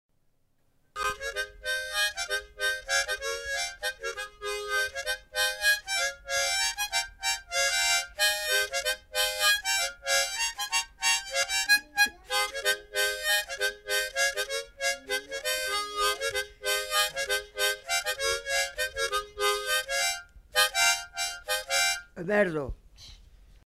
Lieu : Puylausic
Genre : morceau instrumental
Instrument de musique : harmonica
Danse : mazurka